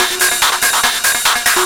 DS 144-BPM B4.wav